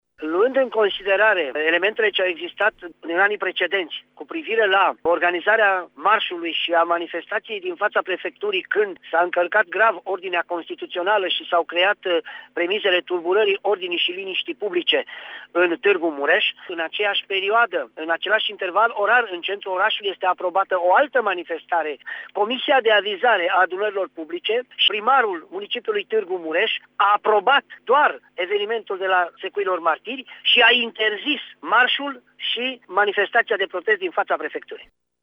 Şeful Poliției Locale Tîrgu-Mureș, Valentin Bretfelean, a explicat că în perioada în care CNS dorea să organizeze marşul şi mitingul este programat un alt eveniment în centrul municipiului: